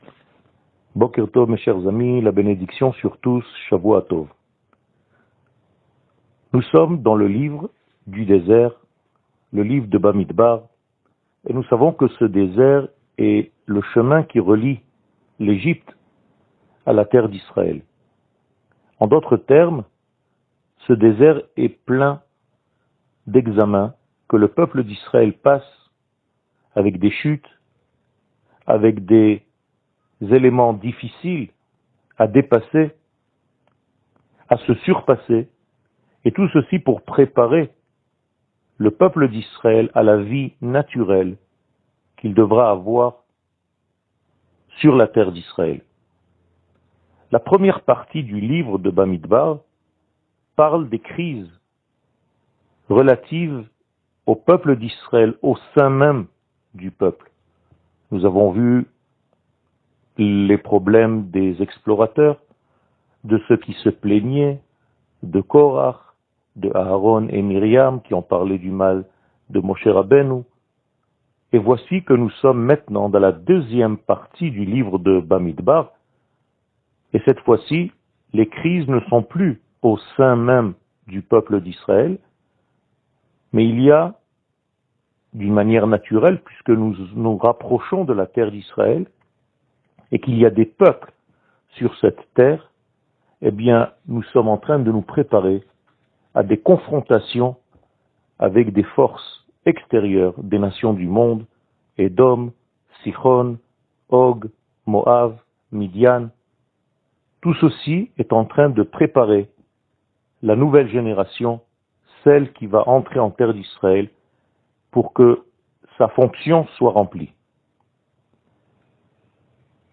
שיעור מ 22 יוני 2021
שיעורים קצרים